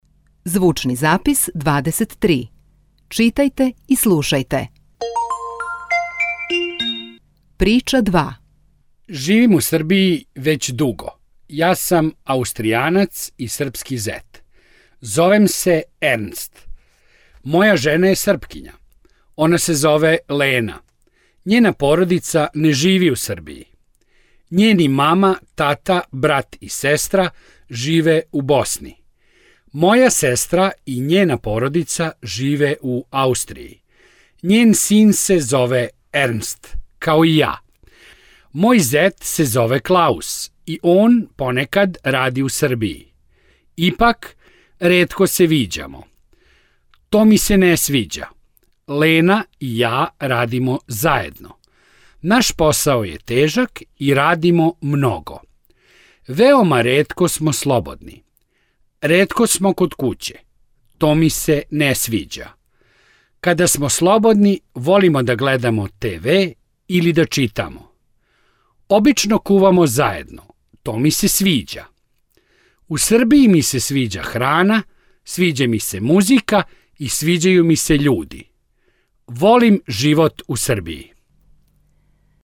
Zbirka audio-zapisa prilagođenih brzinom i vokabularom početnim nivoima predstavlja izuzetno važan i veoma bogat segment udžbenika.